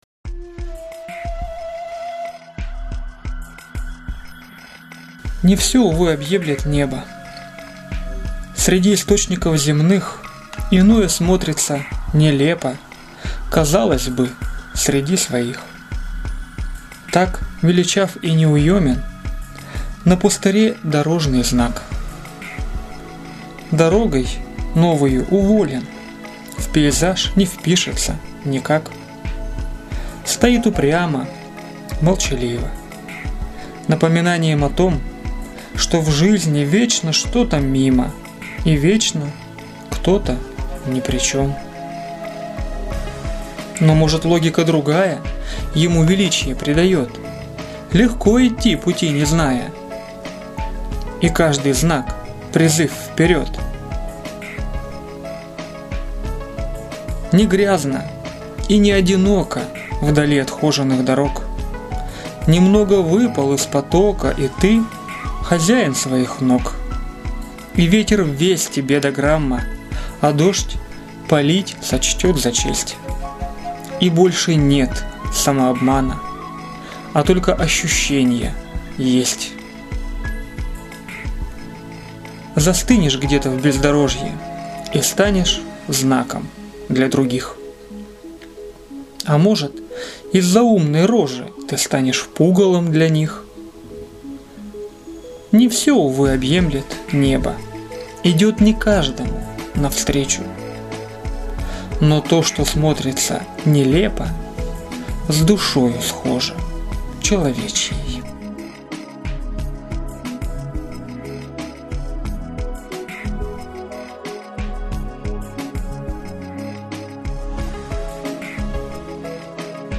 Она, конечно, немного монотонна для моих "сюжетов", но, в целом, подобрал такие стихи, которые тоже довольно "монотонны", где на протяжении звучания самого стихотворения не очень меняется его стиль и содержание.